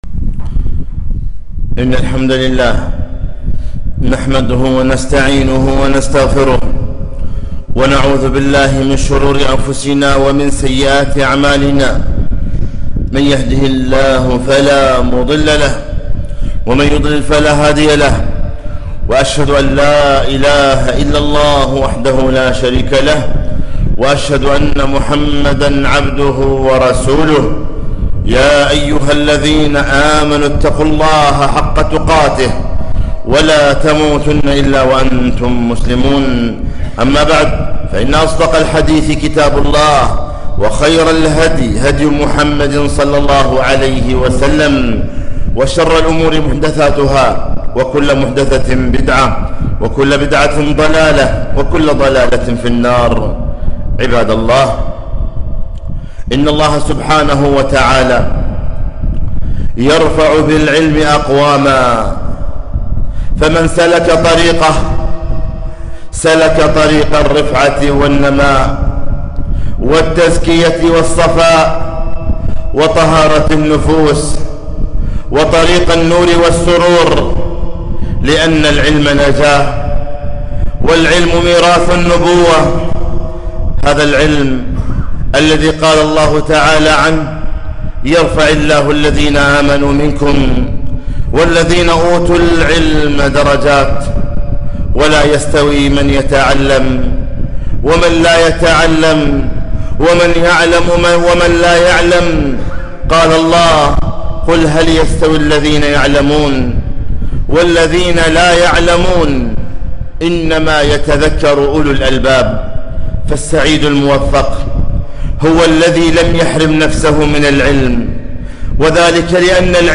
خطبة - استقبال عام دراسي جديد